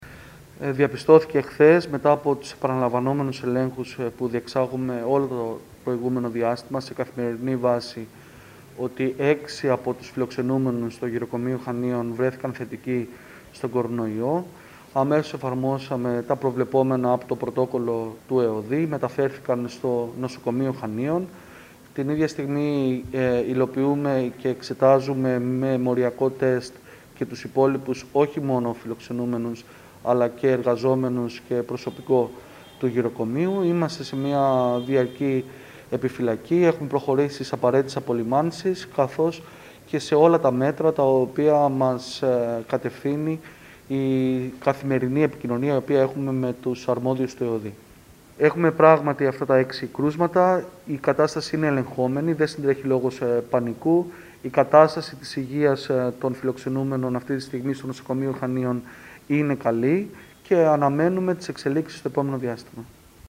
Σε δηλώσεις του στην ΕΡΤ, ο Δήμαρχος Χανίων, Παναγιώτης Σημανδηράκης, τόνισε ότι ακολουθούνται όλες οι προβλεπόμενες διαδικασίες , ενώ διενεργούνται πλέον τεστ, κάθε τρεις μέρες σε όλους τους εργαζόμενους και τους ωφελούμενους της δομής. Ο κ. Σημανδηράκης πρόσθεσε ότι η μεταφορά των ηλικιωμένων στο νοσοκομείο έγινε για προληπτικούς λόγους, καθώς οι περισσότεροι είναι ασυμπτωματικοί: